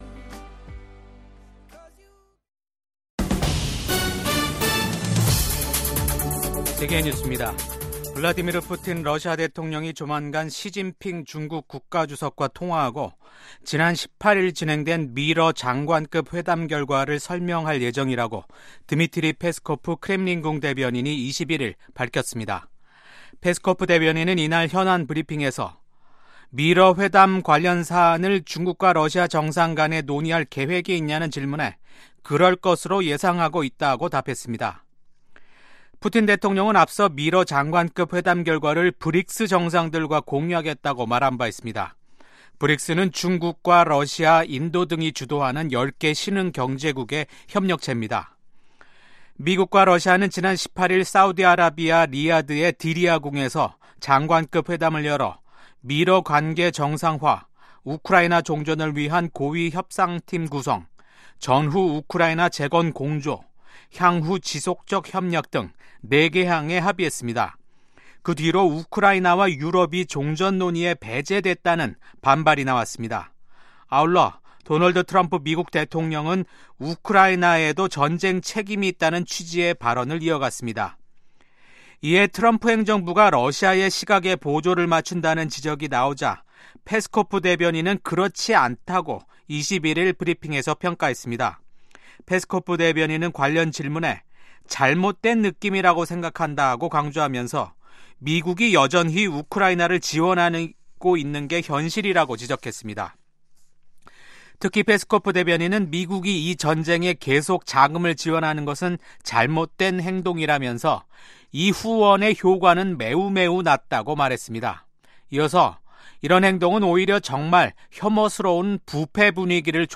VOA 한국어 아침 뉴스 프로그램 '워싱턴 뉴스 광장'입니다. 미국 백악관 국가안보보좌관은 도널드 트럼프 대통령이 김정은 북한 국무위원장을 비롯한 독재자들에 맞설 수 있는 유일한 인물이라고 밝혔습니다. 러시아에 파병된 북한군의 전투력이 낮아 우크라이나 군에 압도당하고 있다고 전 유럽주둔 미군 사령관이 평가한 가운데 유엔 인권기구는 우크라이나군에 생포된 러시아 파병 북한군을 본국으로 돌려보내서는 안 된다는 입장을 밝혔습니다.